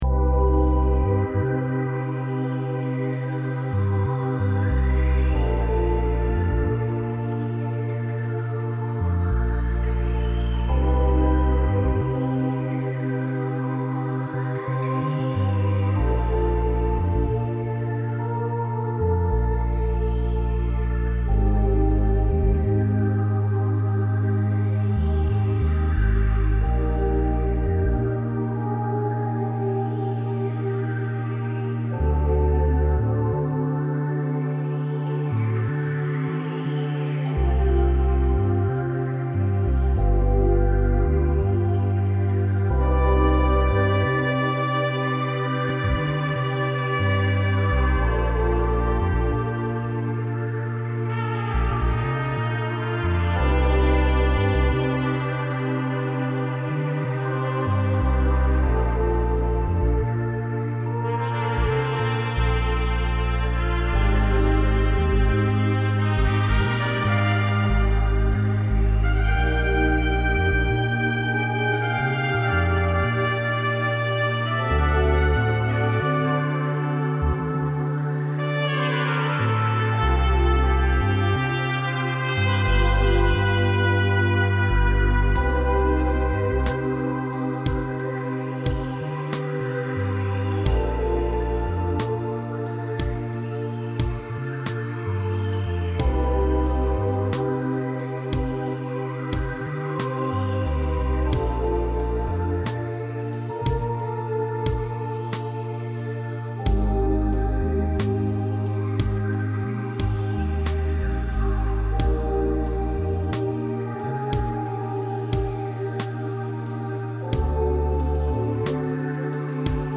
A collab piece
trumpet, drums
All other instruments and sounds were played by myself on an electric keyboard that ran into ProTools.